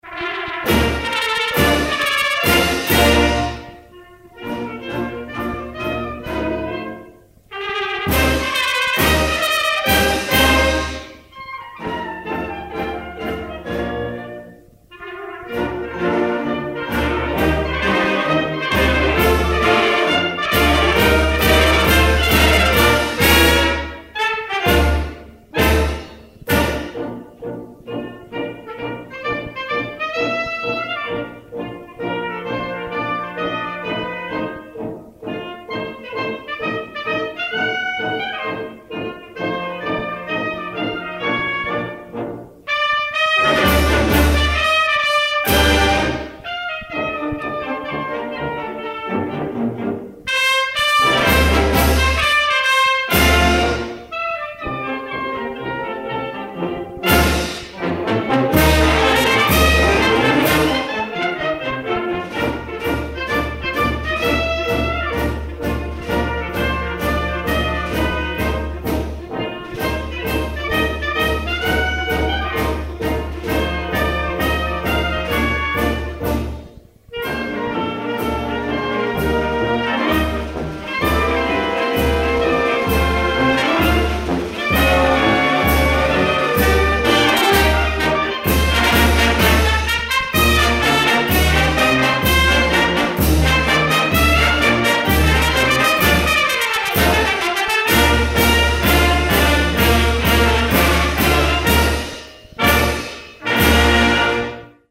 Diese Version ist jedoch nicht die welche auf dem Siegerpodest bei Senna Siegen als gespielt wurde.